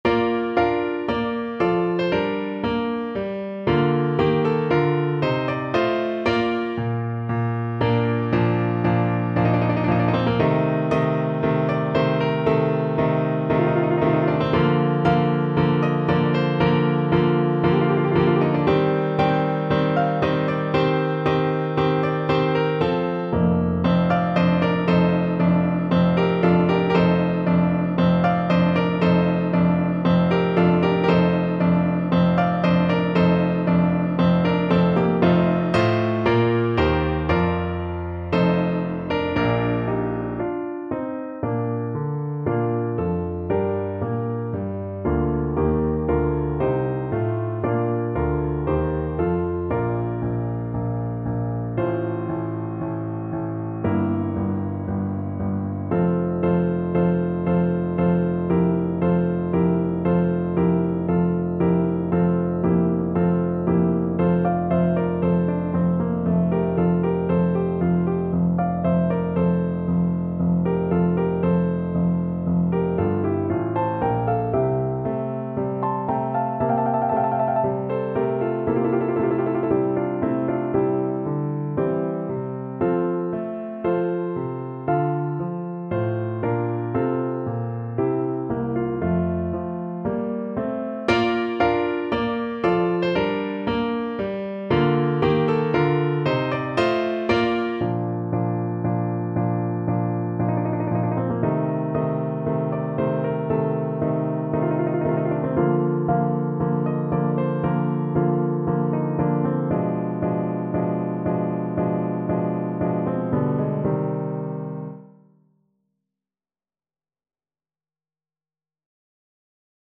Play (or use space bar on your keyboard) Pause Music Playalong - Piano Accompaniment Playalong Band Accompaniment not yet available reset tempo print settings full screen
4/4 (View more 4/4 Music)
Bb major (Sounding Pitch) (View more Bb major Music for Tenor Voice )
Andante ( = c.116)
Classical (View more Classical Tenor Voice Music)